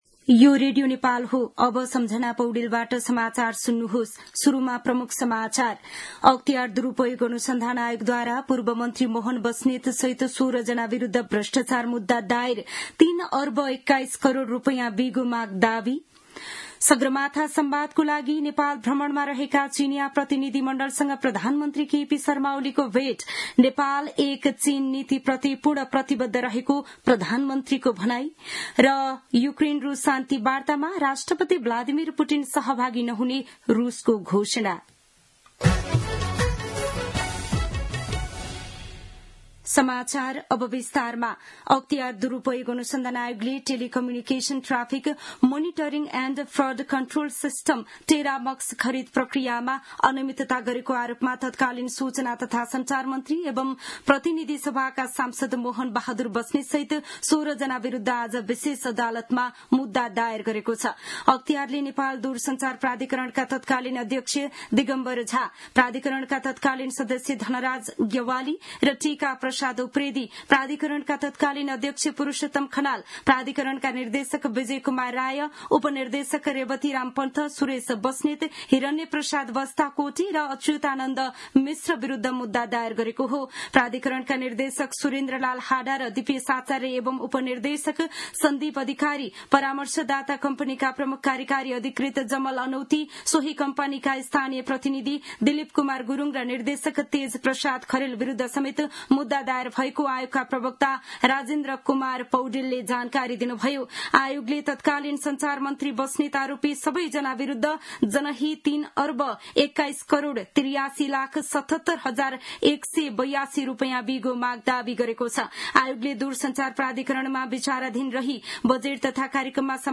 दिउँसो ३ बजेको नेपाली समाचार : १ जेठ , २०८२
3-pm-news-1-2.mp3